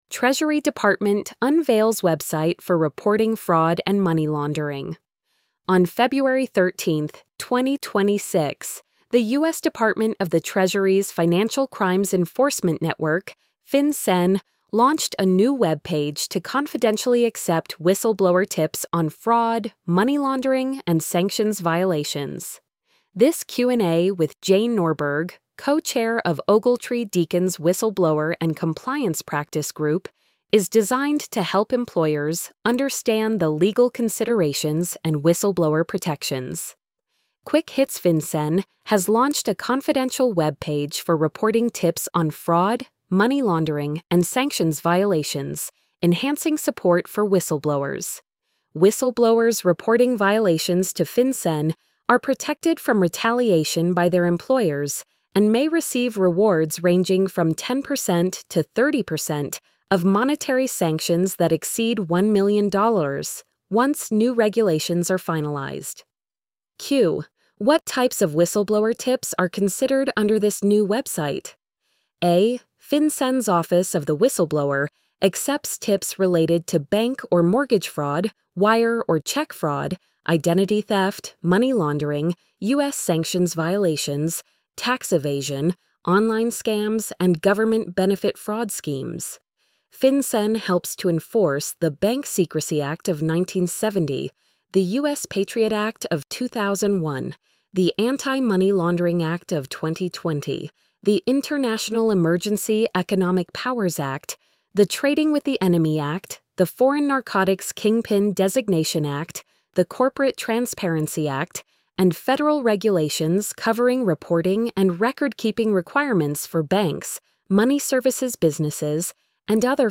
treasury-department-unveils-website-for-reporting-fraud-and-money-laundering-tts.mp3